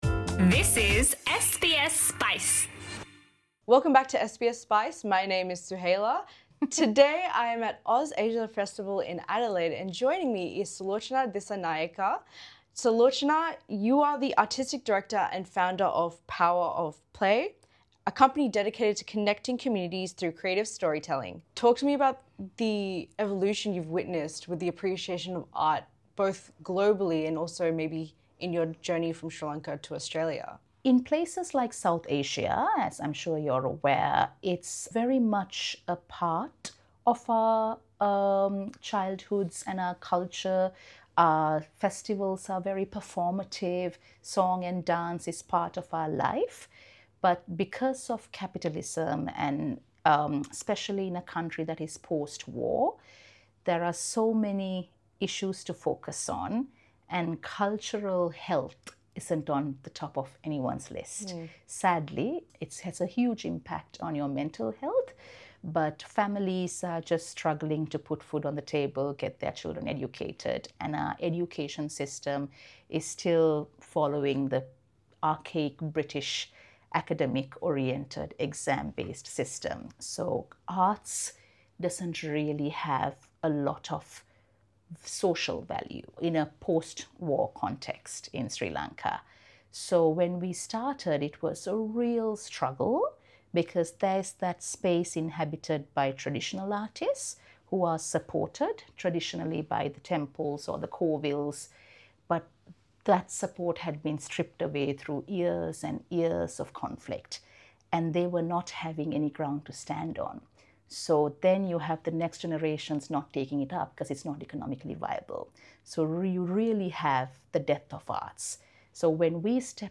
SBS Spice attended the OzAsia Festival, thanks to the festival organisers.